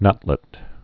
(nŭtlĭt)